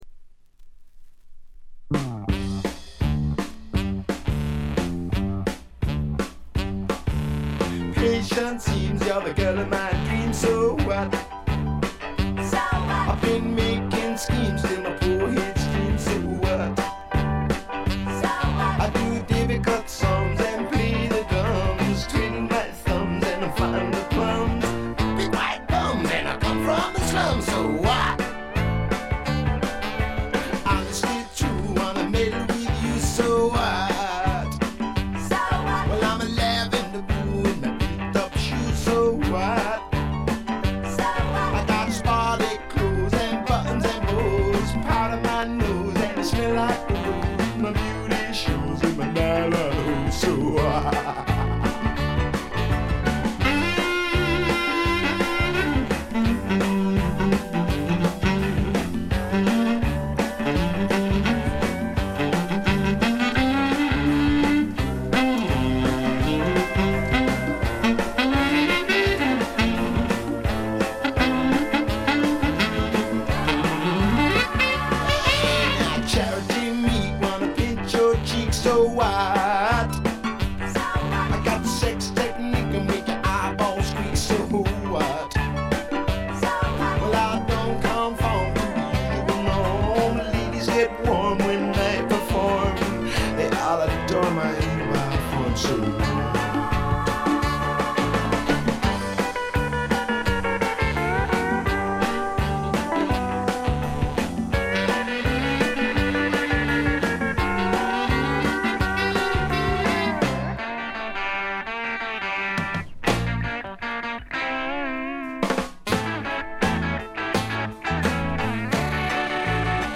部分試聴ですがチリプチ少々。
後のブロックヘッズのような強烈な音もいいですが、本作のようなしゃれたパブロックも最高ですね！
試聴曲は現品からの取り込み音源です。